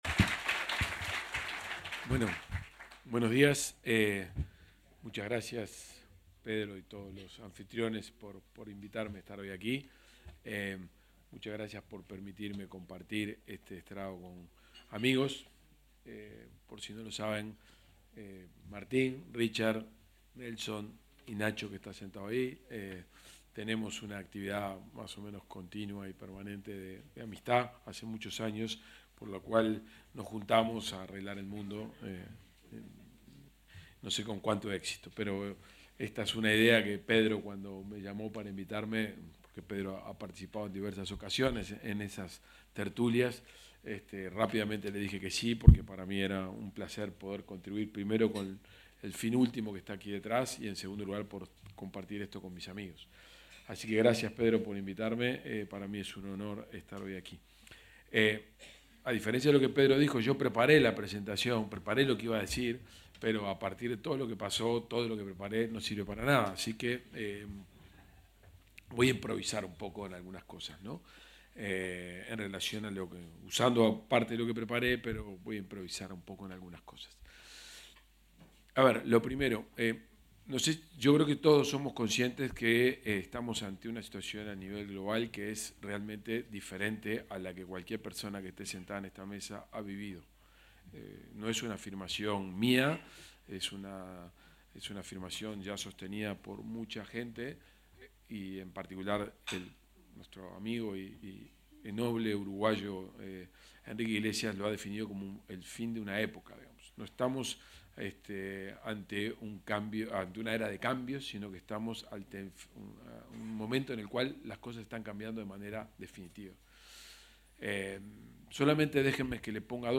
Palabras del ministro de Economía, Gabriel Oddone
El ministro de Economía y Finanzas, Gabriel Oddone, expuso en un desayuno de trabajo organizado por la fundación Disciplinas Aplicadas al Trabajo (DAT